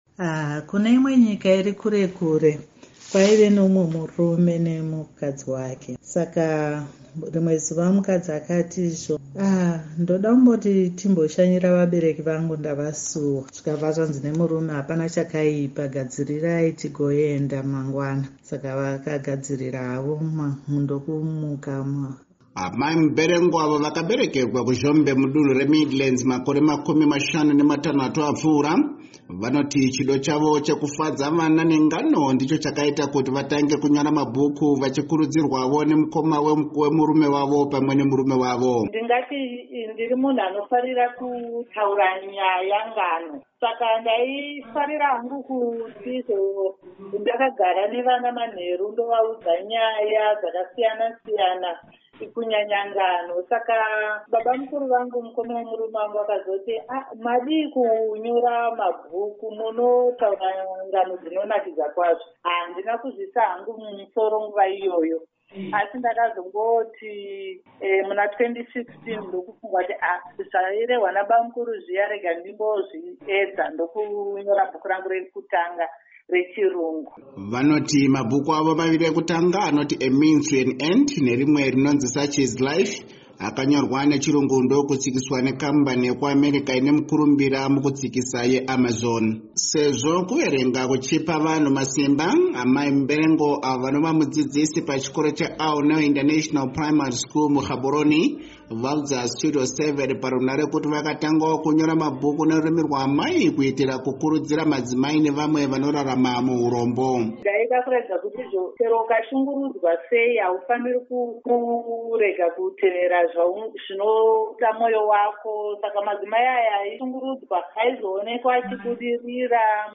vaudza Studio7 parunhare kuti vakatangawo kunyora mabhuku nerurimi rwaamai kuitira kukurudzira madzimai nevamwe vanorarama muhurombo.